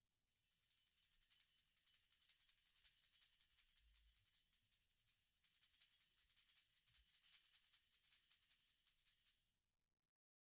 Tamb1-Roll_v1_rr1_Sum.wav